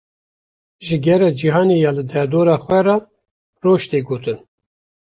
/roːʒ/